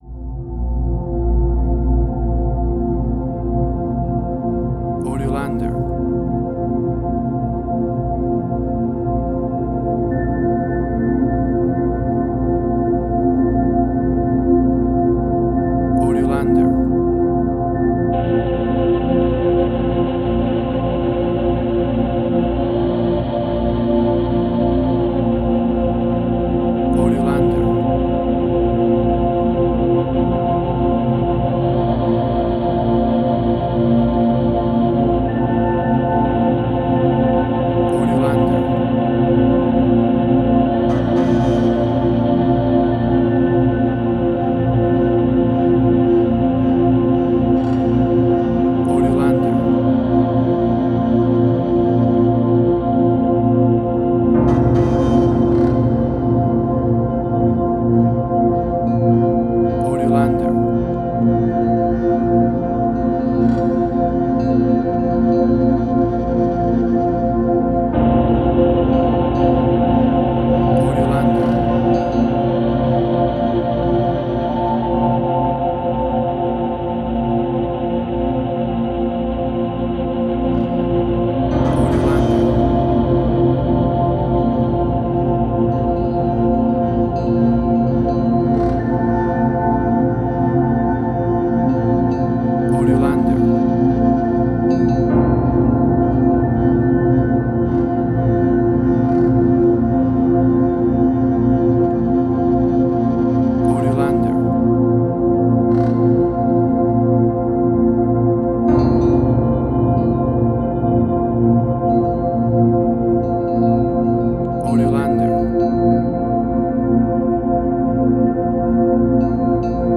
Soundscapes Similar Cliff Martinez, Atticus Ross.
WAV Sample Rate: 16-Bit stereo, 44.1 kHz